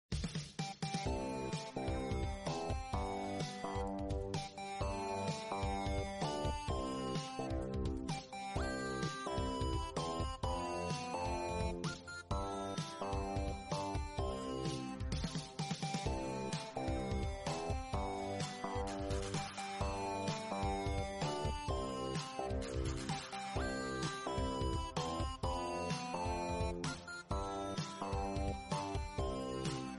Instrumental Song